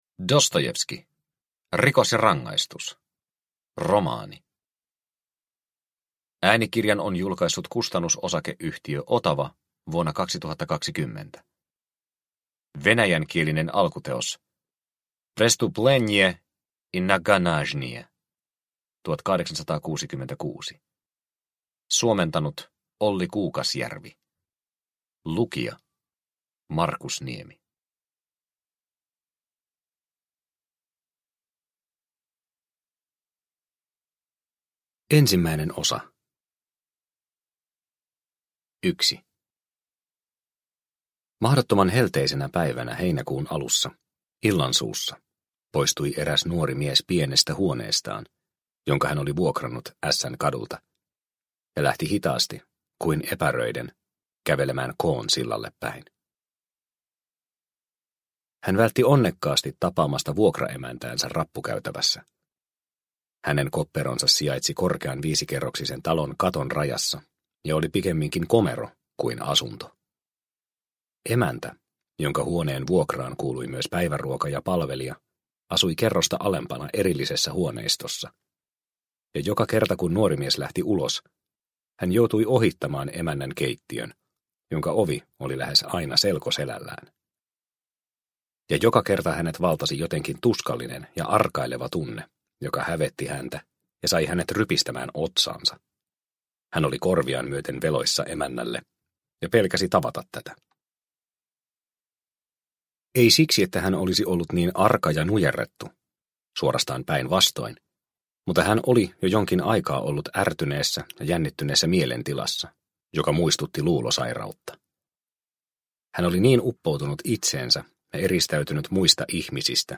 Rikos ja rangaistus – Ljudbok – Laddas ner